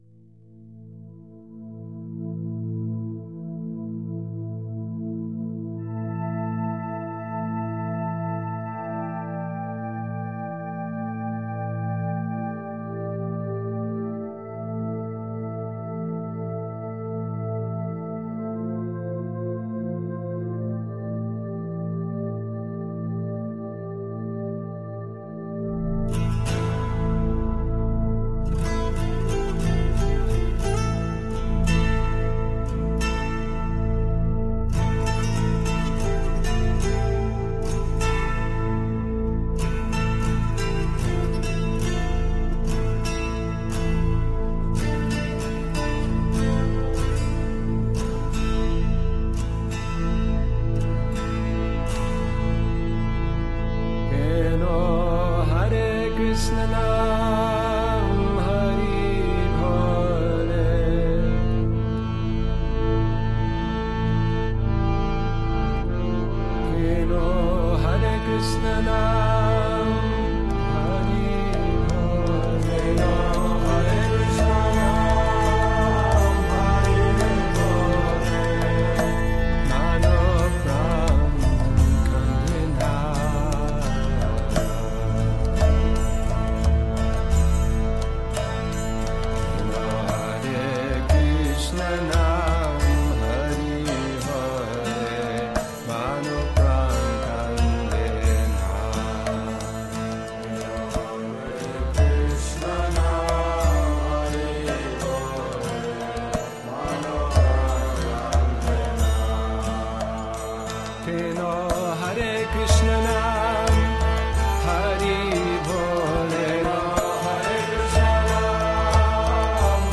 It is of superb digital quality.
playing saxophone, flute and violin
and many hundreds of ecstatic and enthusiastic Vaishnavas.